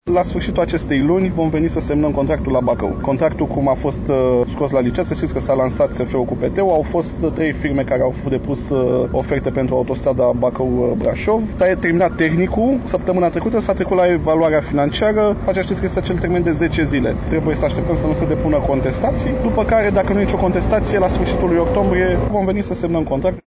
(AUDIO/FOTO) Vizită a ministrului Transporturilor pe șantierul Variantei Ocolitoare Bacău